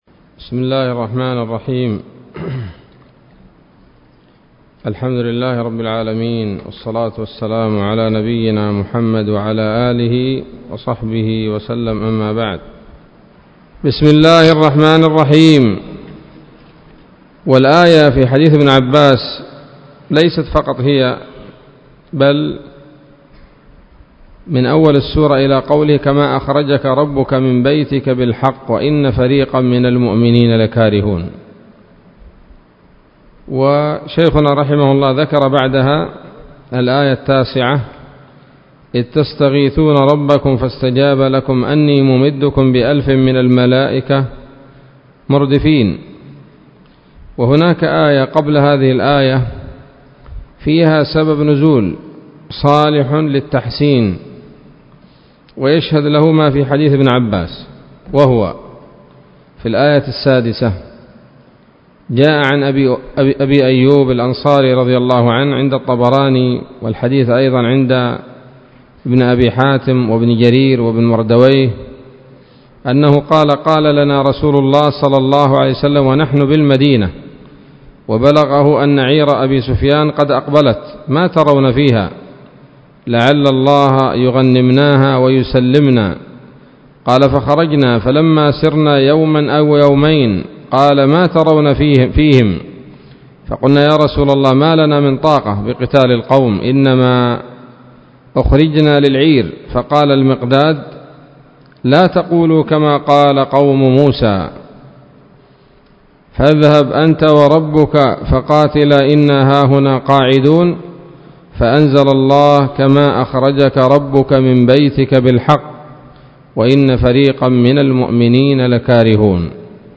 الدرس التاسع والثلاثون من الصحيح المسند من أسباب النزول